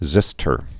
(zĭstər)